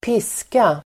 Uttal: [²p'is:ka]
piska.mp3